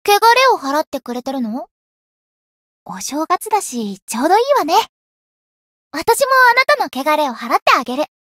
灵魂潮汐-神纳木弁天-春节（摸头语音）.ogg